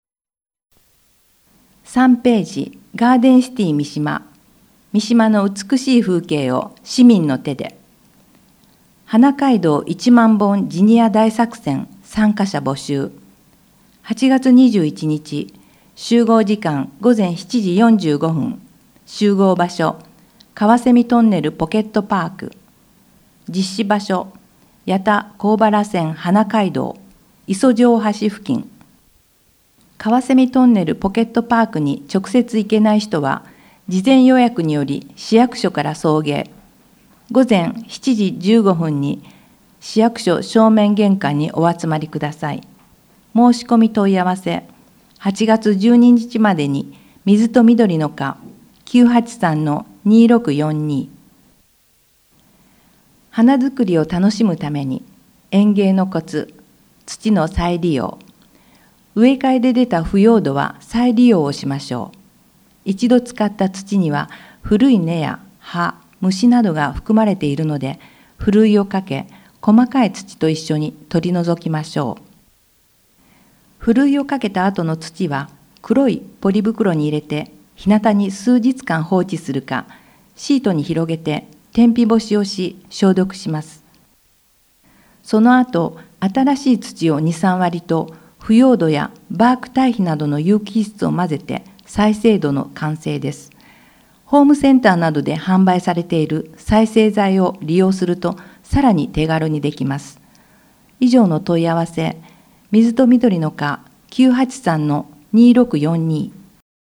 三島市社会福祉協議会で活動するボランティアグループ「やまなみ」は、視覚障害者への情報提供として「広報みしま」毎月１日号・１５日号を録音し、声の広報として送り届ける活動をしています。